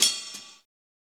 Closed Hats
HIHAT_FIGHT_U.wav